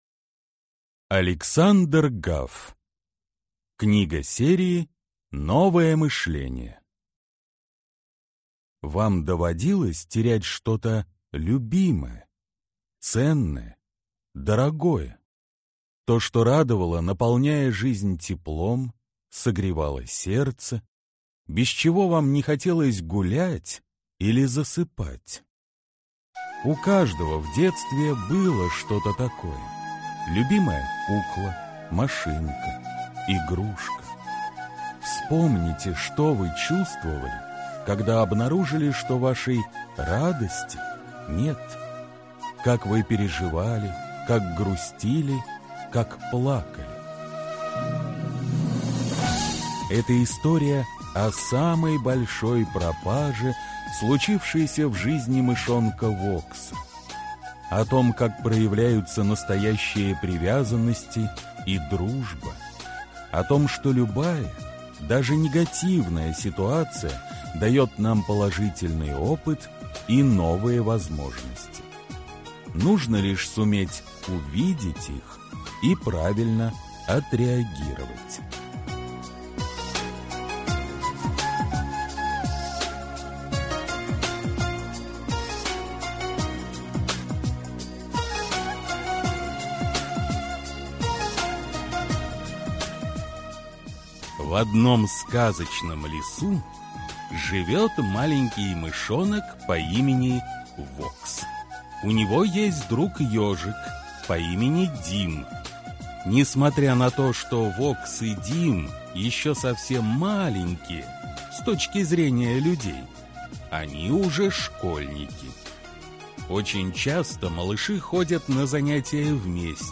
Аудиокнига Мышонок Вокс и пропавшее время | Библиотека аудиокниг